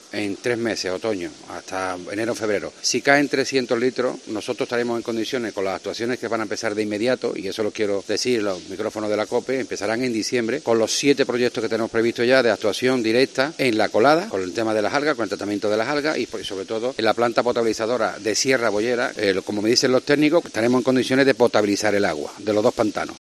Así lo ha asegurado en COPE el presidente de la Diputación, Salvador Fuentes, que da de plazo hasta "febrero o marzo" para tratar hacer el agua apta para consumo humano